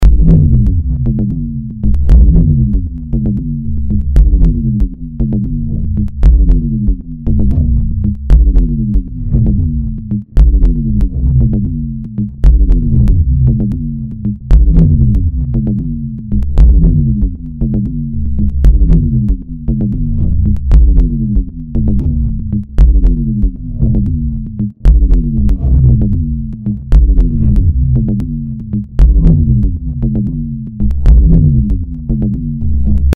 Wie gesagt: das Klicken ist gewollt und soll Patina beisteuern.